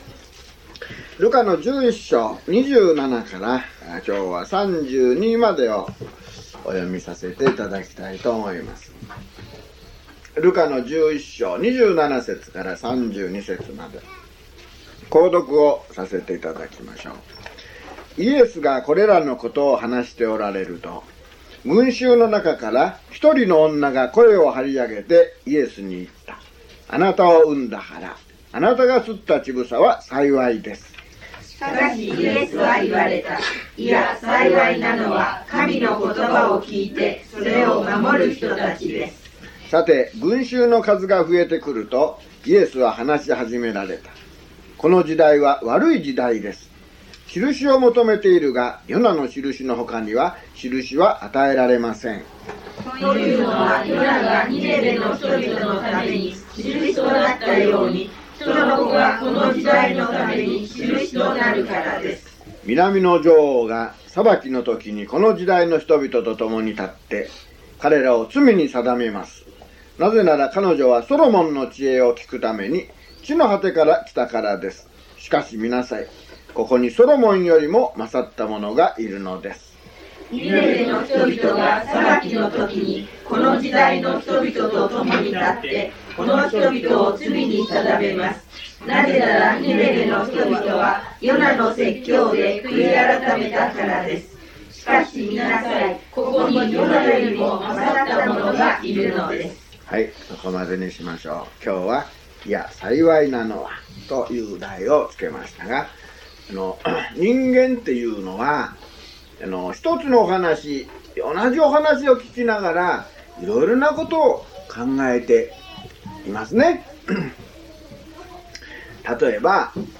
luke082mono.mp3